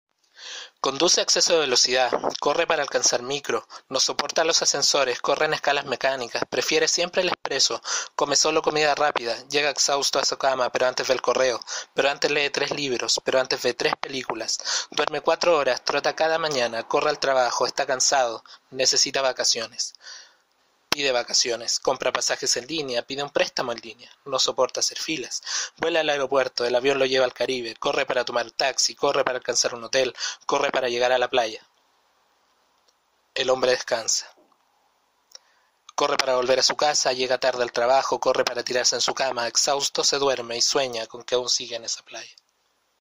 leer un poema